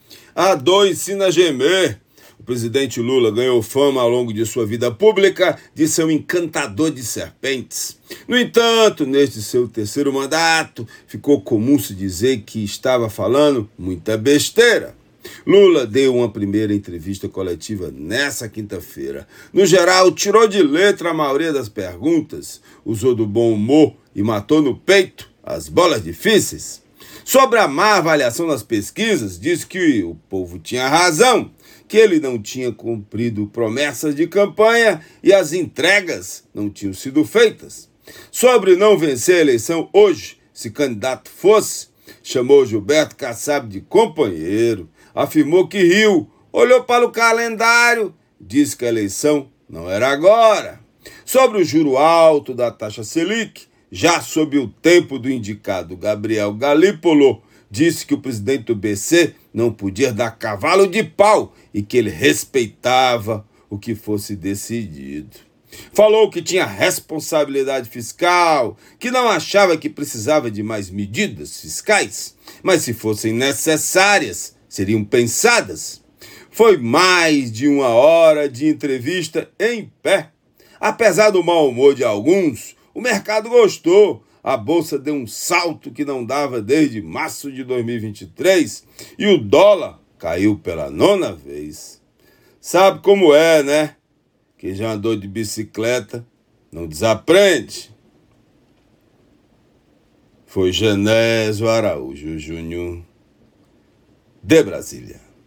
Comentário do jornalista